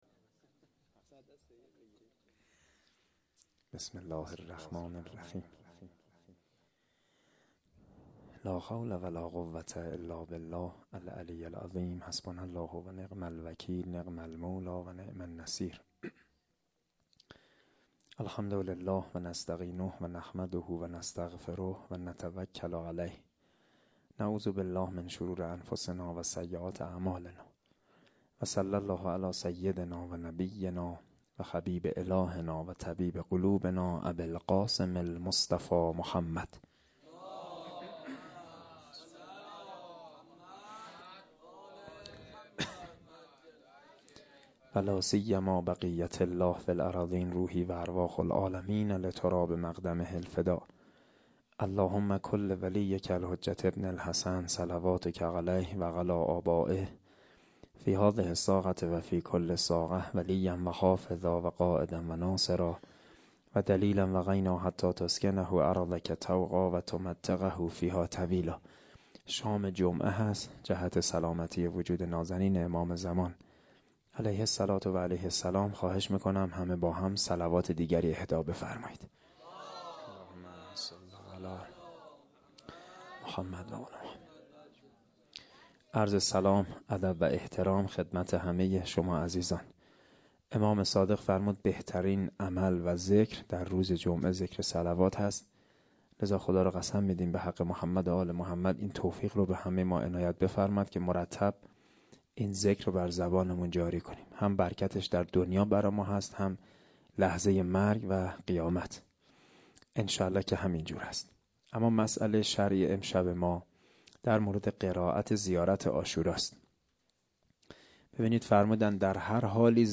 01asheghan-sokhanrani7.lite.mp3